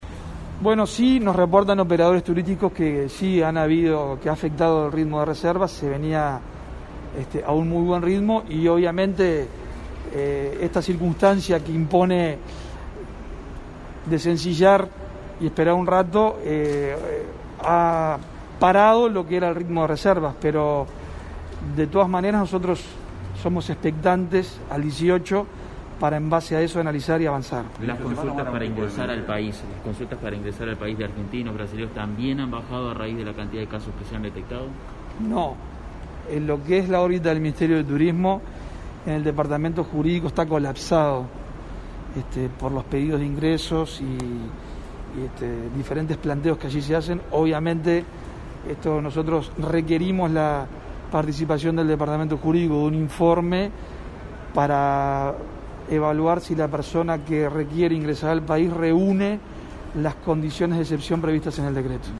Se ha enlentecido el ritmo de reservas inmobiliarias y hoteleras para la temporada estival debido al ascenso de casos de Covid-19, así lo informó en rueda de prensa el ministro de Turismo, Germán Cardoso.